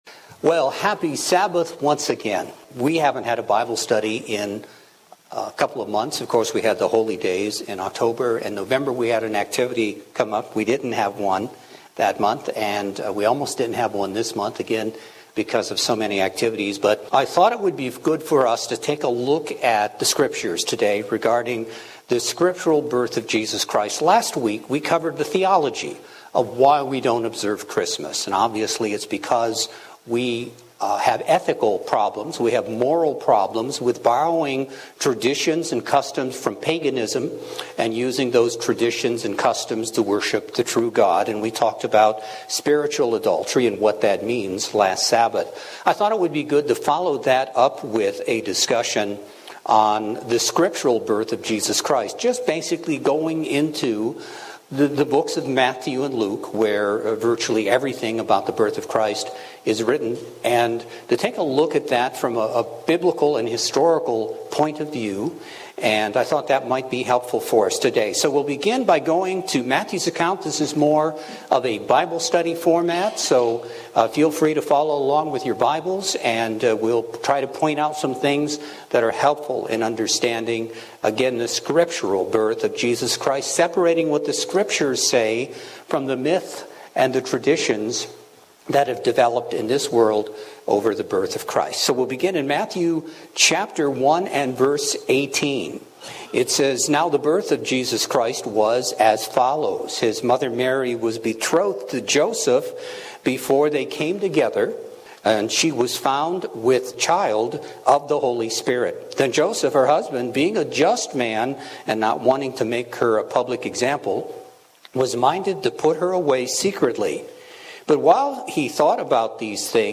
In a world of myths, confusion and error... let's go to the gospels of Matthew and Luke to discover what the Scriptures tell us about the birth of Jesus Christ. Today we will use a Bible Study format.